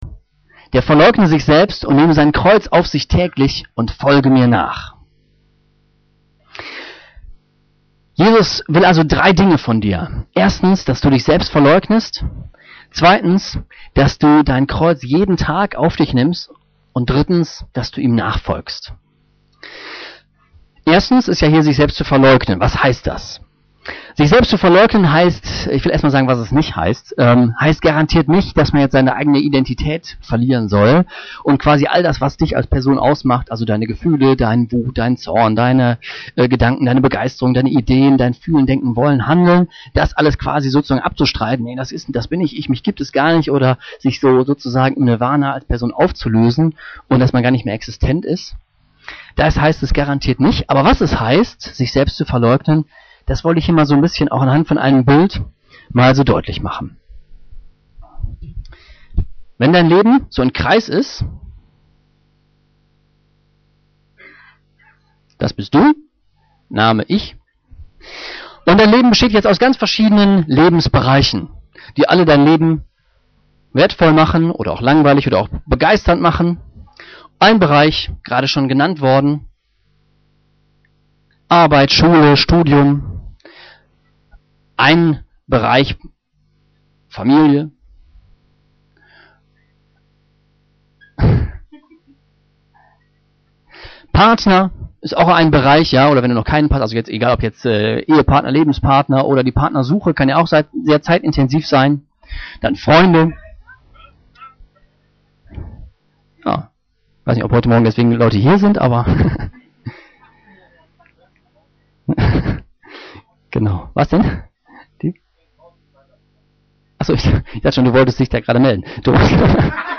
Auf dieser Seite findest du unsere Predigten der letzten 18 Jahre.